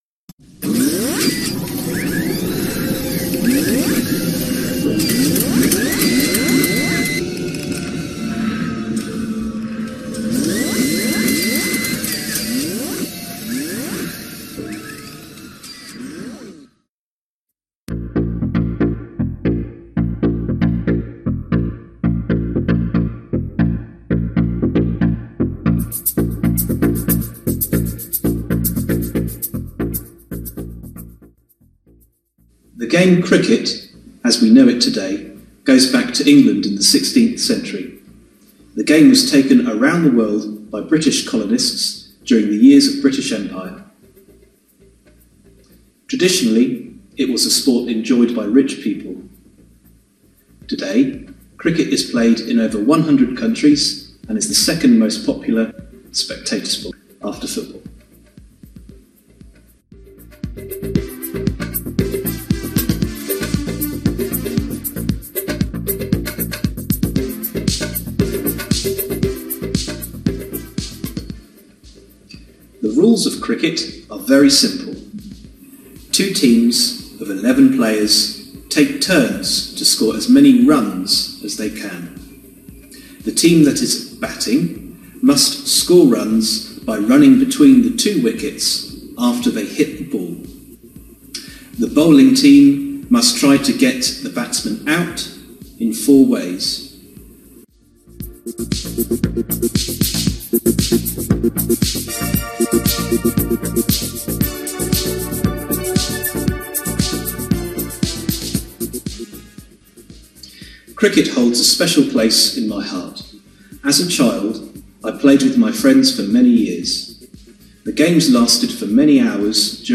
CRICKETTHEINTERVIEW.mp3